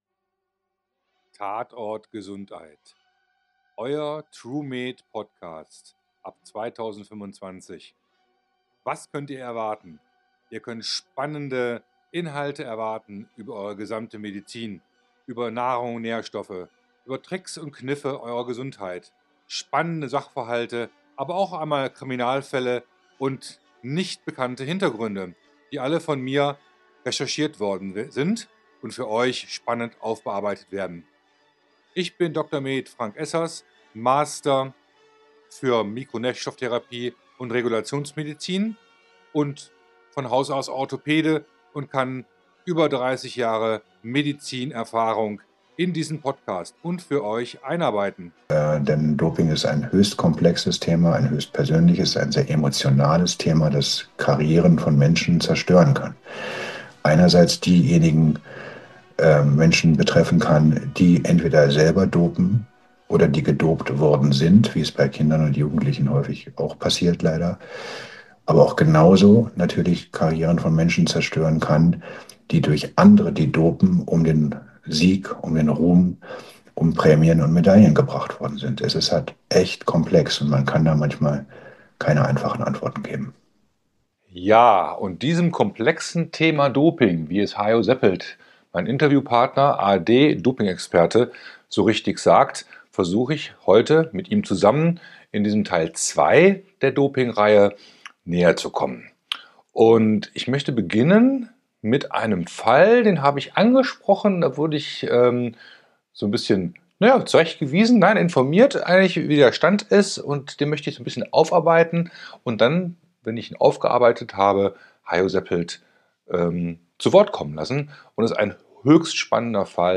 Vol.2, Der ARD-Experte Hajo Seppelt im Interview, Teil2 , #68